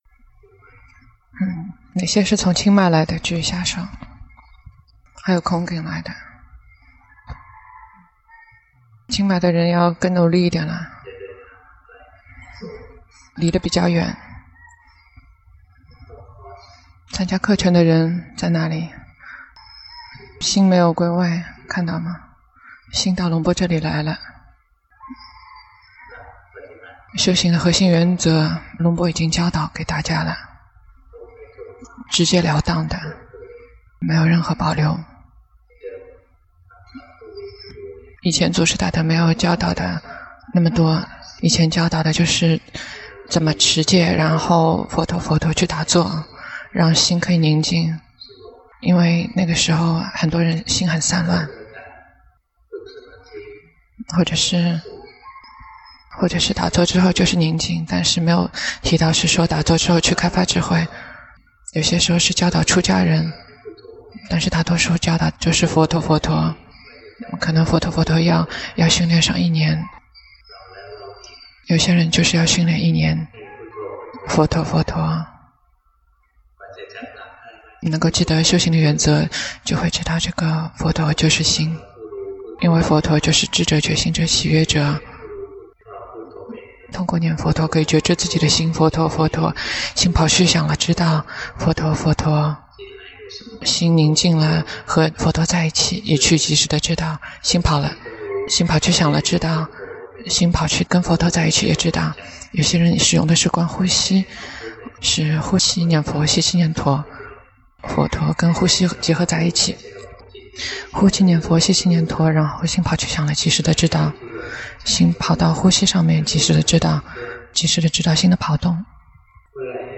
長篇法談｜覺知自己的智相應大善心